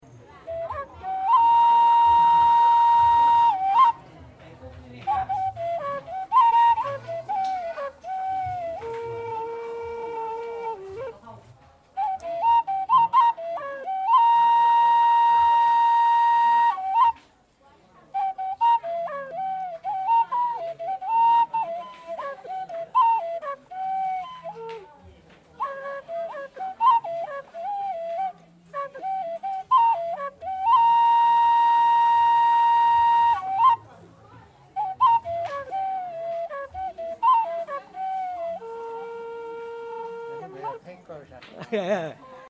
duct flute solo recorded on Doi Pui at the New Year festival 665KB